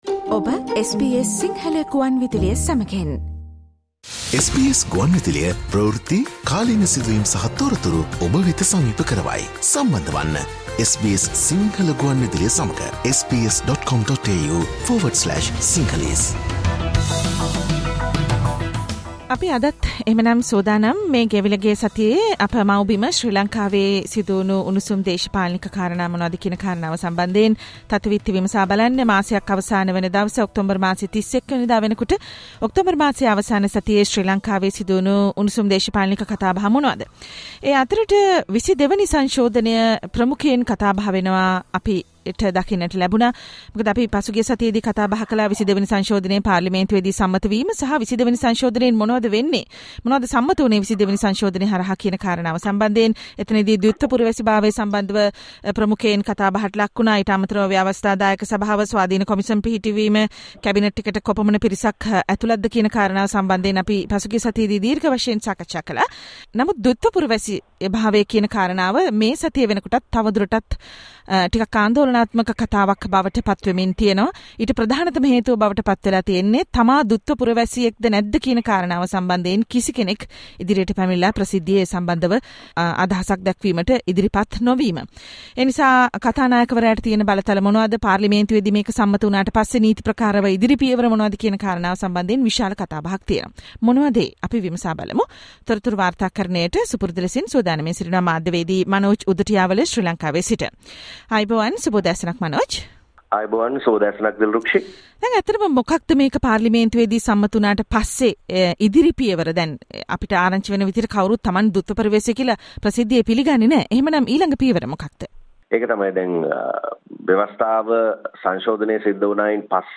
Sri Lankan political news wrap of the week from SBS Sinhala
SBS Sinhala radio brings you the most prominent news highlights of Sri Lanka in this featured current affair segment every Monday.